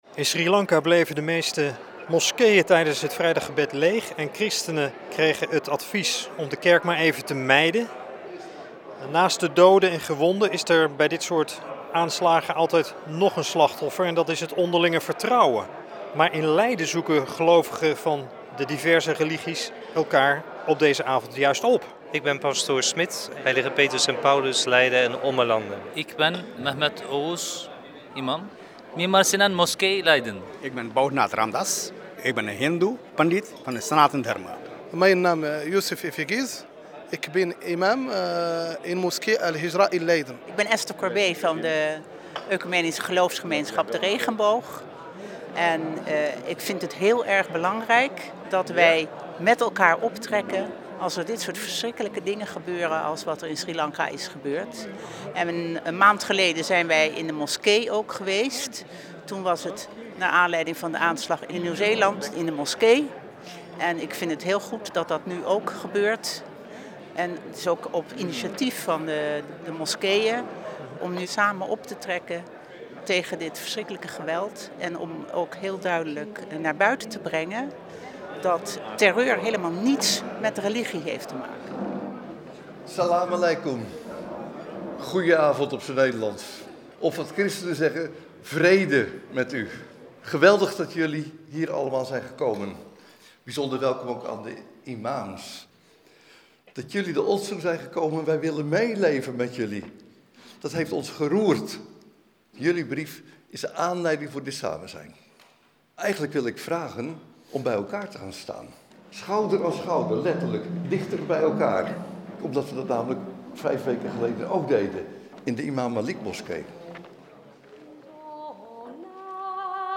Op de bijeenkomst: Terreur heeft geen religie van 26 april 2019 was een verslaggever van het radio 1 journaal aanwezig.
Verslag op Radio 1 van Terreur heeft geen religie
Bijeenkomst-in-Leiden-NOS.mp3